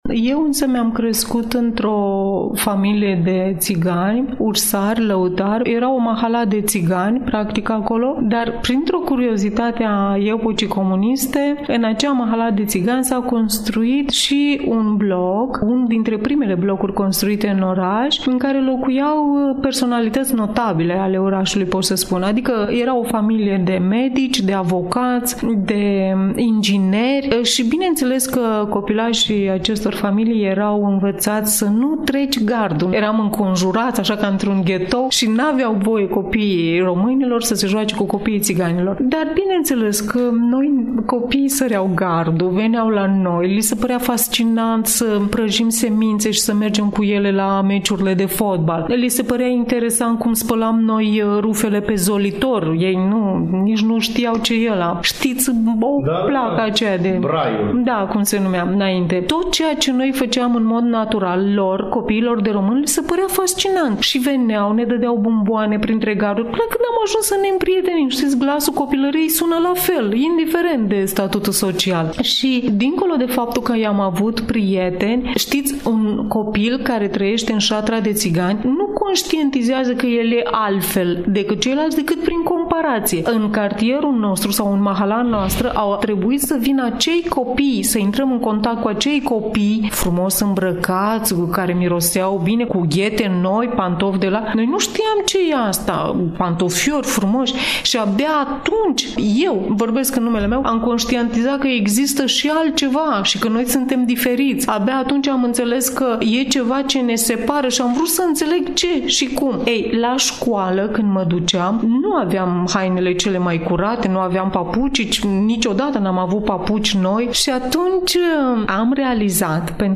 ne vorbește cu pasiune, răbdare, dar și cu lacrimi în ochi